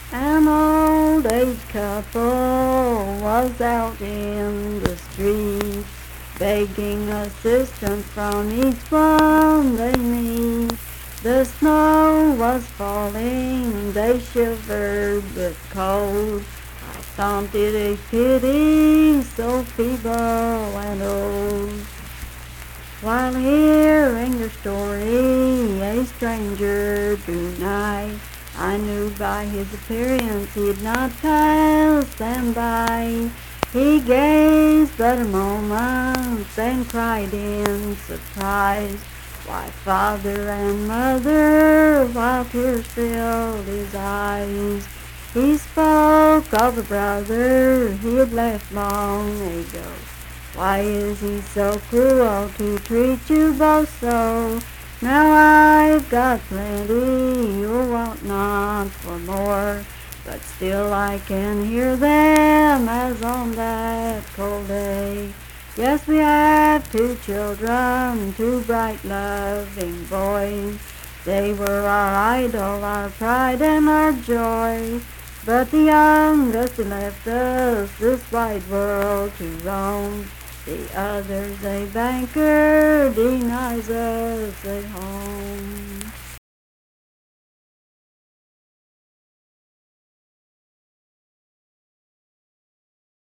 Unaccompanied vocal music
Verse-refrain 4(4).
Voice (sung)